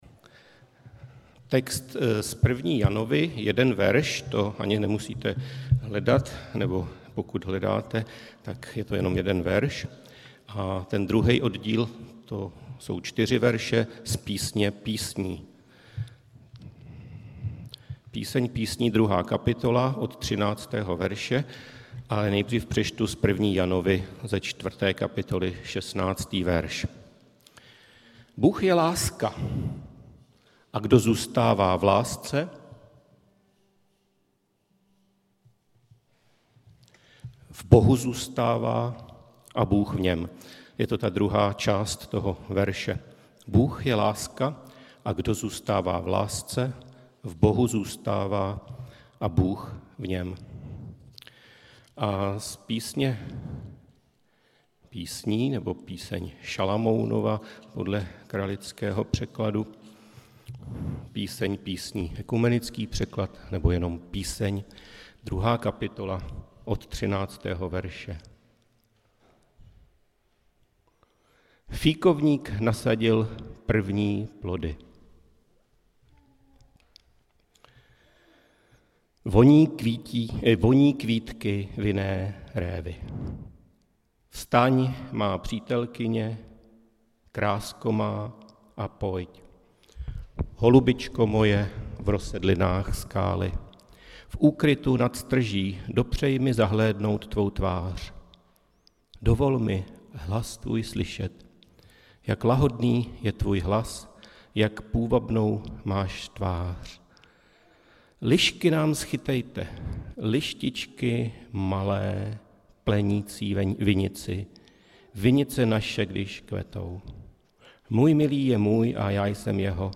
Událost: Kázání